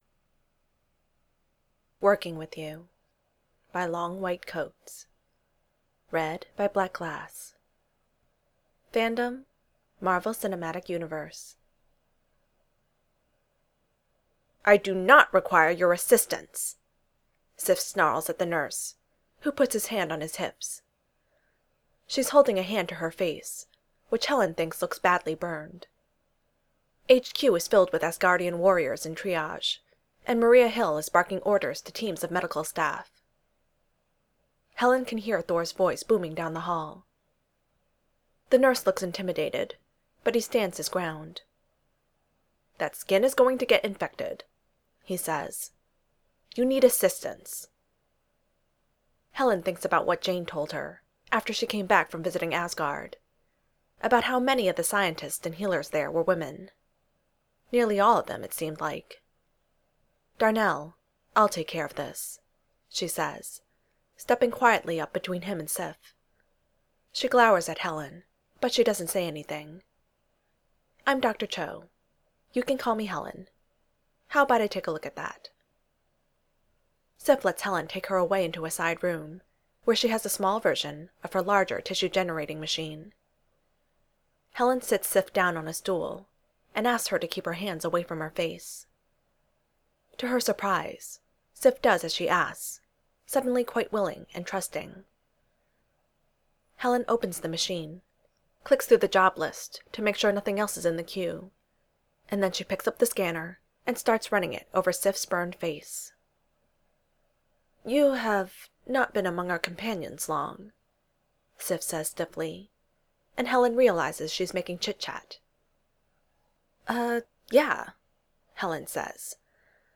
audiofic archive